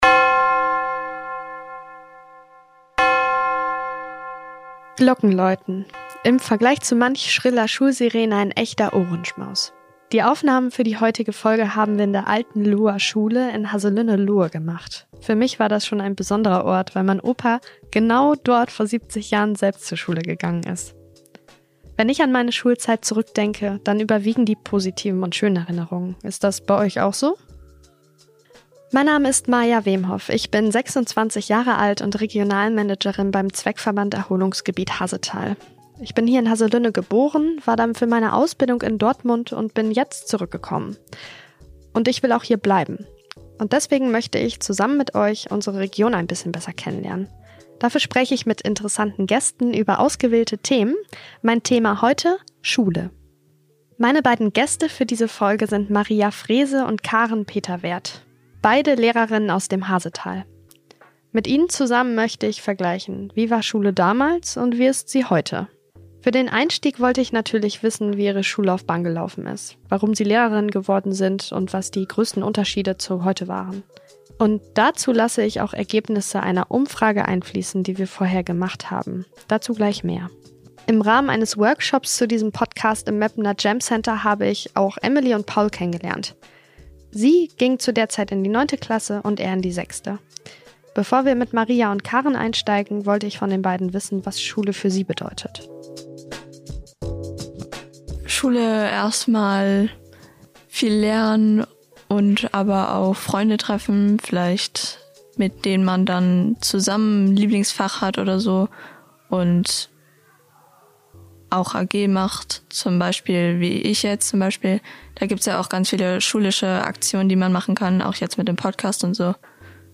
Passend dazu haben wir den Podcast in der alten Loher Schule in Haselünne-Lohe aufgenommen.